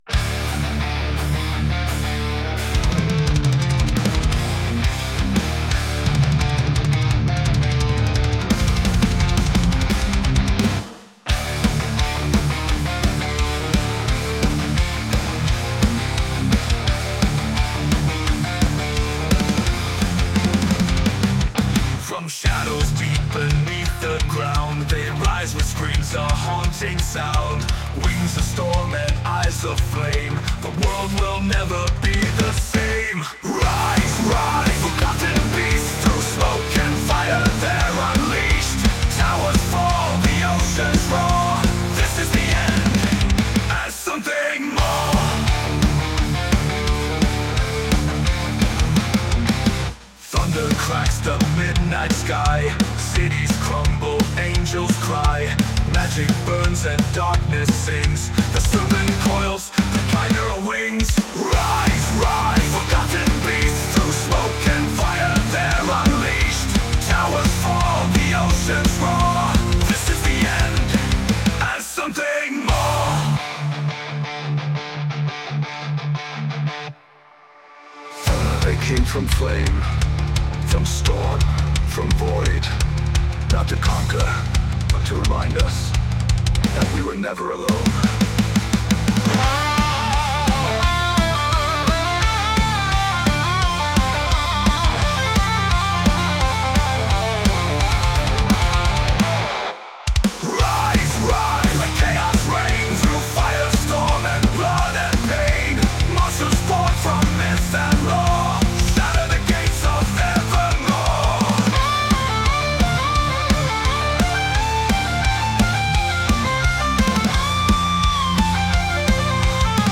J'explore l'IA musicale avec Suno pour composer des morceaux originaux : ambiances, thèmes narratifs, musiques cinématiques ou vocals stylisés.